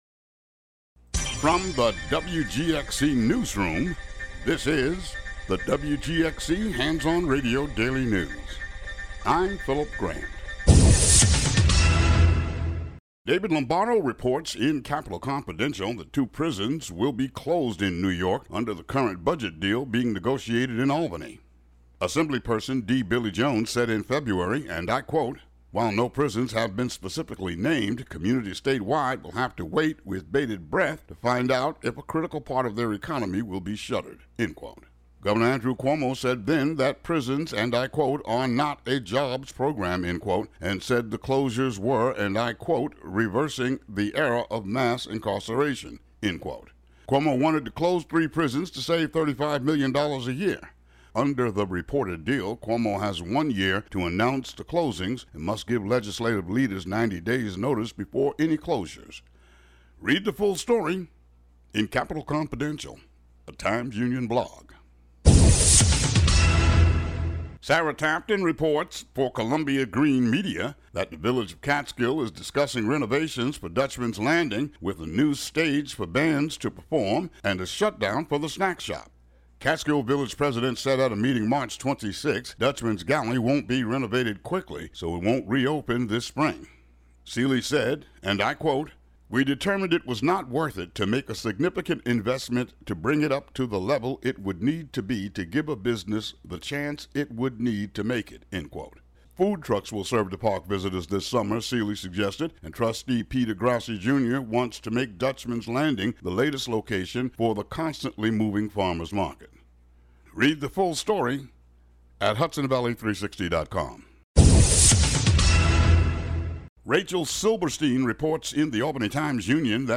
Today's daily news.